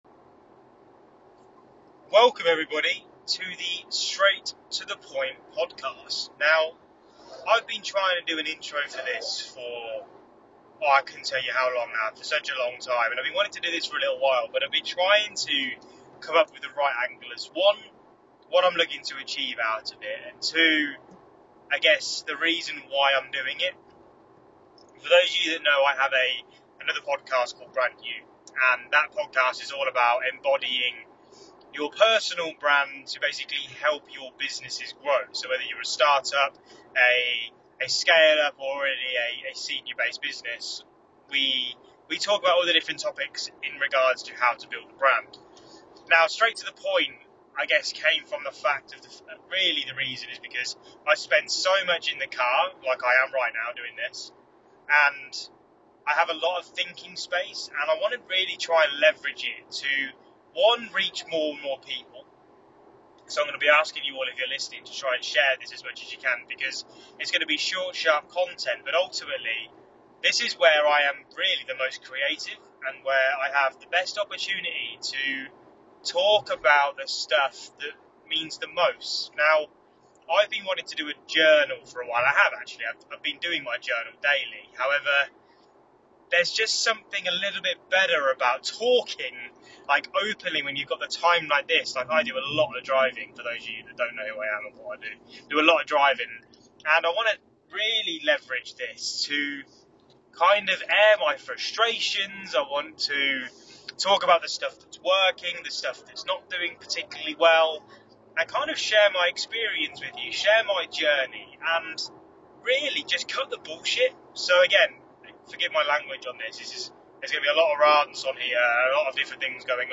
Look, the audio won't be amazing, but I promise the content will be.